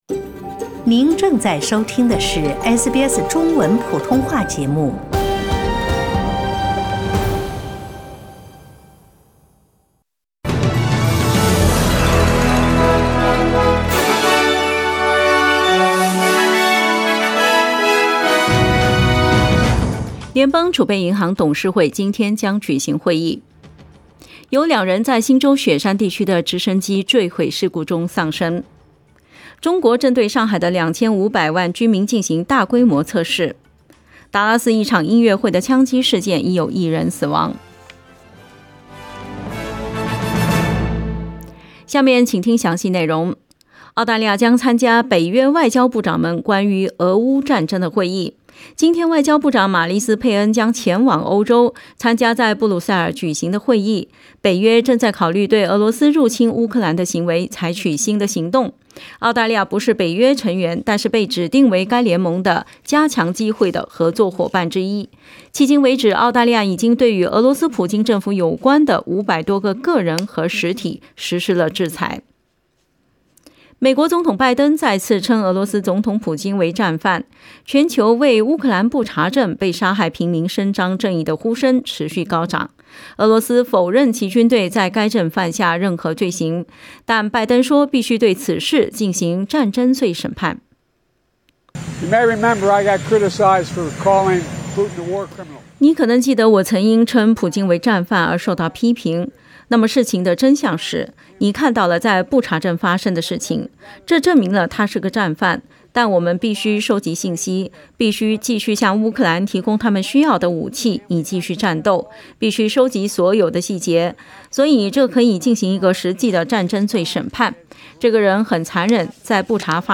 SBS早新闻（4月5日）
SBS Mandarin morning news Source: Getty Images